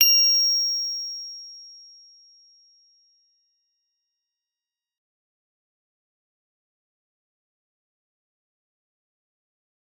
G_Musicbox-F7-f.wav